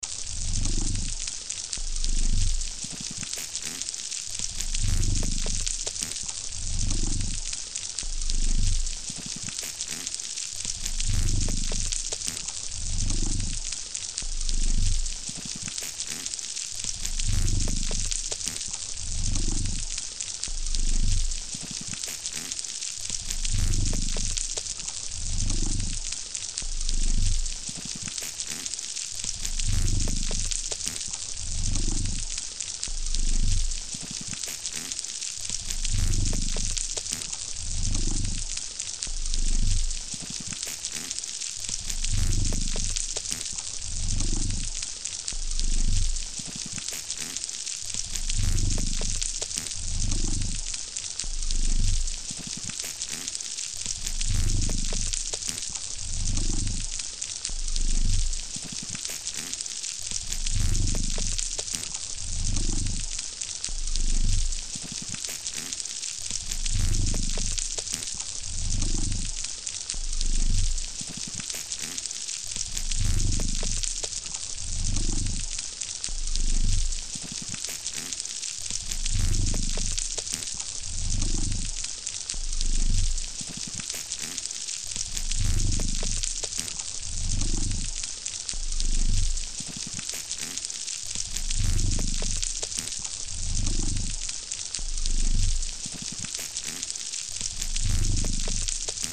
Sounds of the reef | Natural History Museum
Listen to an underwater recording of the Great Barrier Reef and find out why it sounds like frying bacon.
The ‘frying’ sound is produced by snapping shrimp (Alpheidae).
The frying sound is the result of lots of these shrimp hunting in the same area.
The sound is so loud, the snapping shrimp competes with much larger creatures, such as the blue whale, to be the loudest animal in the ocean.
sound-of-coral-reef.mp3